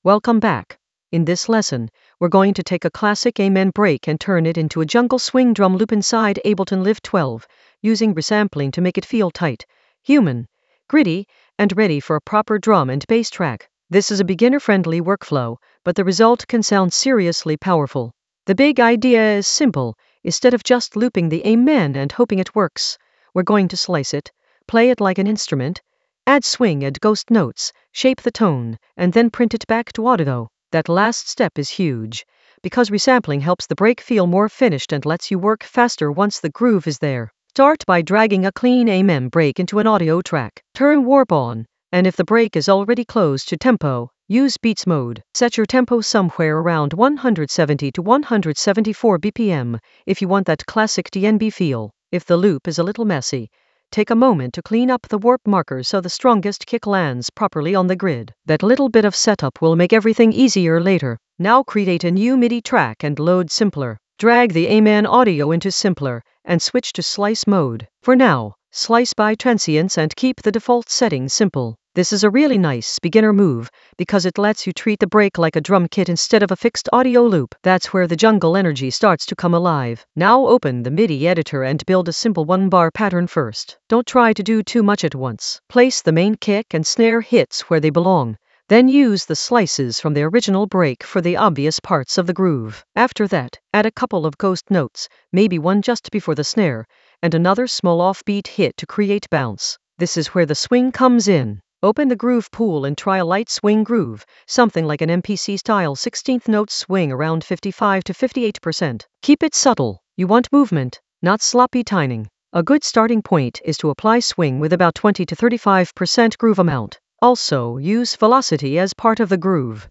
An AI-generated beginner Ableton lesson focused on Amen Ableton Live 12 edit workflow with jungle swing in the Resampling area of drum and bass production.
Narrated lesson audio
The voice track includes the tutorial plus extra teacher commentary.